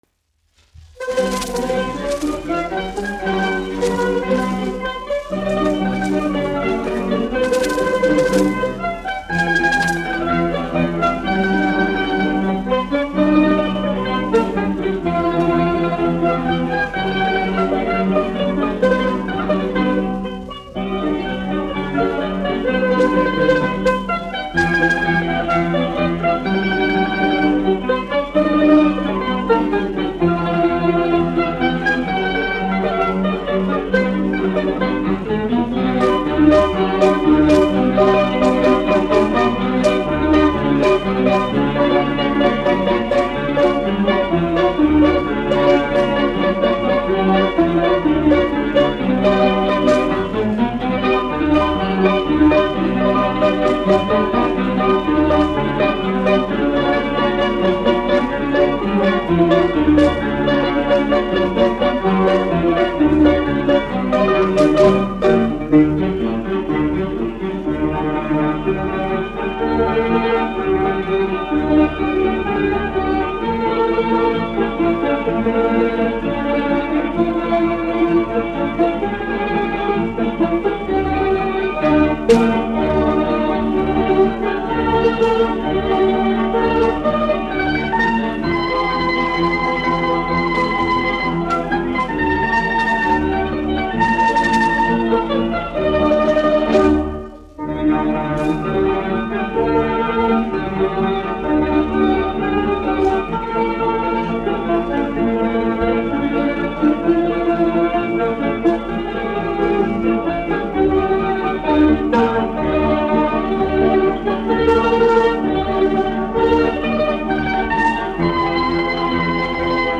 1 skpl. : analogs, 78 apgr/min, mono ; 25 cm
Marši
Orķestra mūzika
Skaņuplate